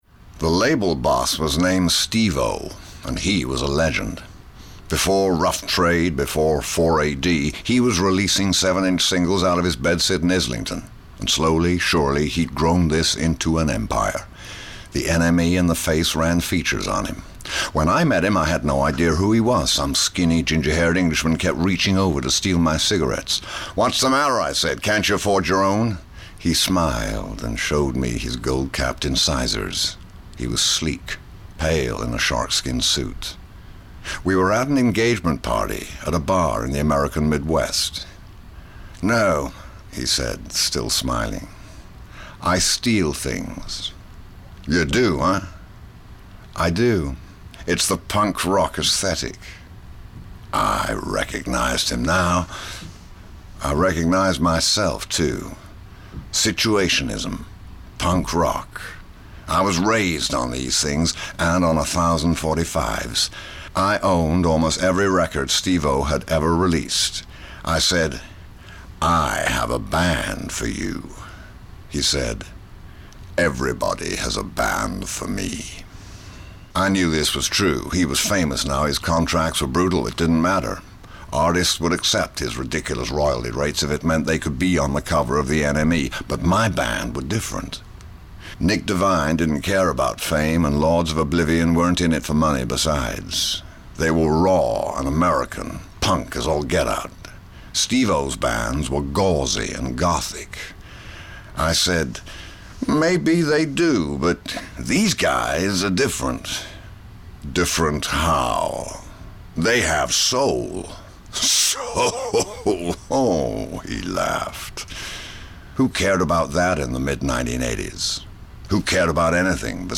“The Devil In It Somewhere” – TSS excerpt read by Jeremy Irons